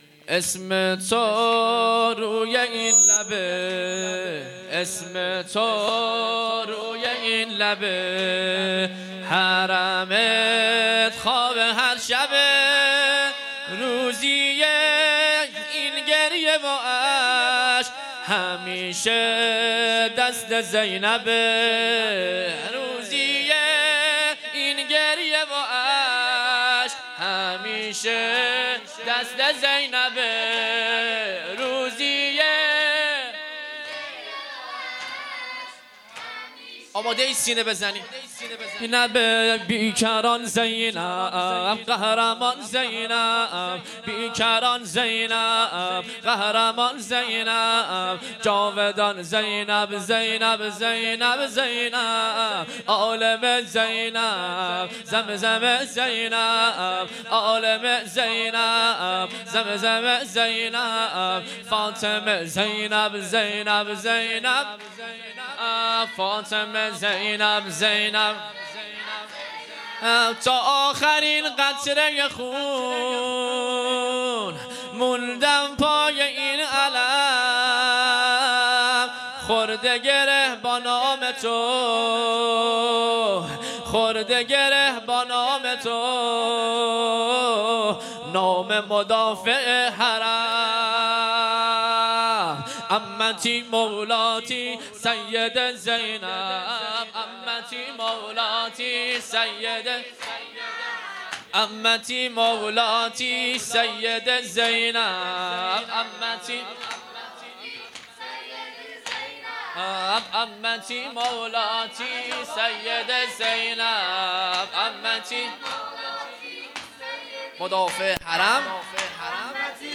هیئت‌ هفتگی انصار سلاله النبی